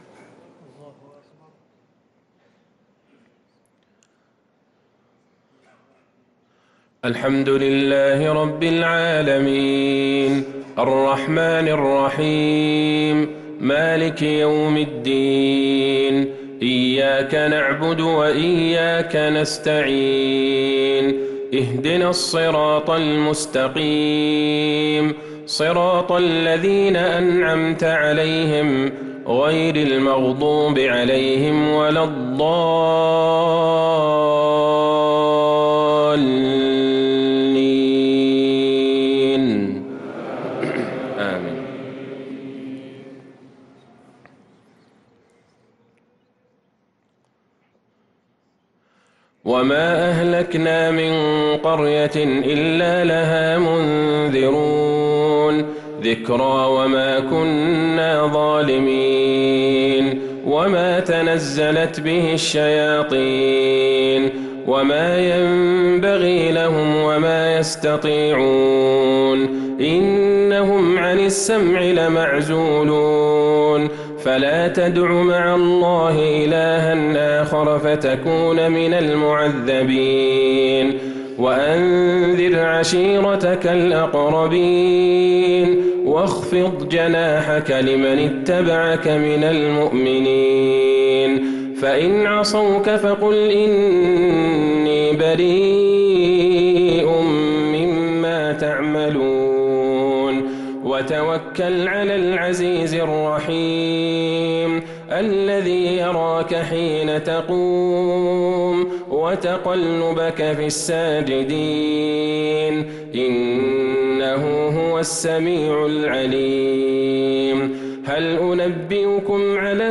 صلاة العشاء للقارئ عبدالله البعيجان 23 رجب 1445 هـ